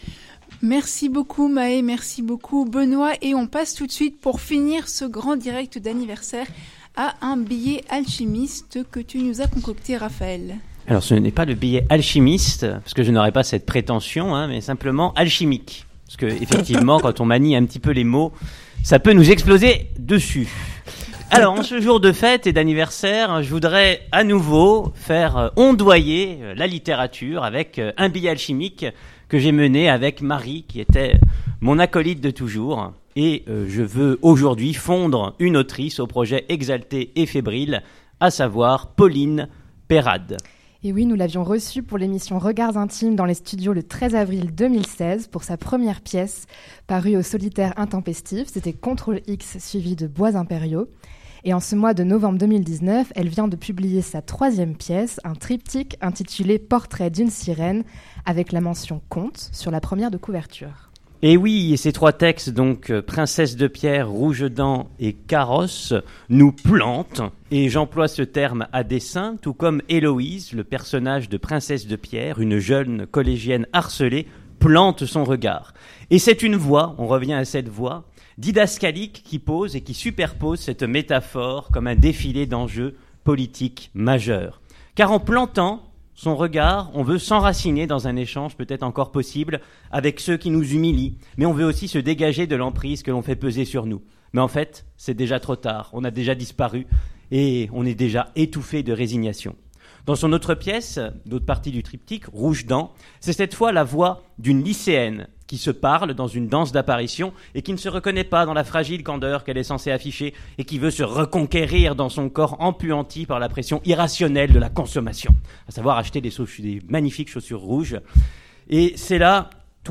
Critique de livre
Billet Alchimique enregistré en direct pour les 10 ans de Trensistor à l’ENS (samedi 30 novembre 2019) de Lyon